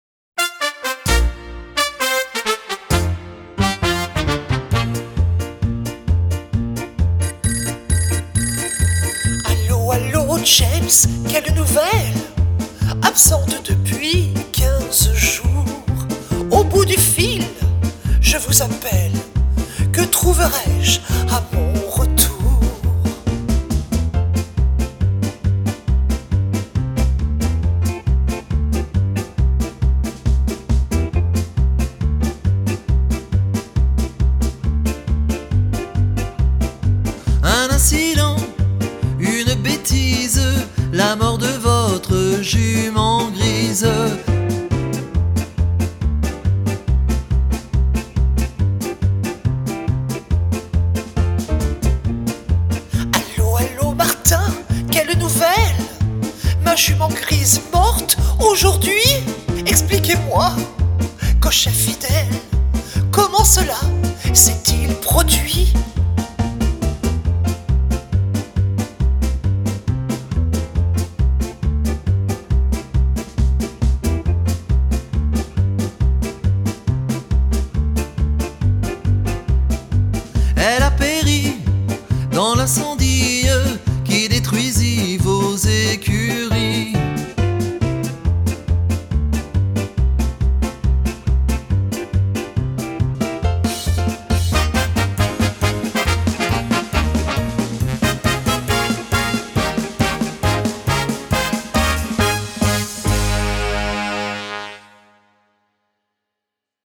La version chantée avec les trous (Expert)